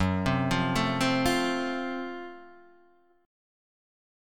F#mM7b5 Chord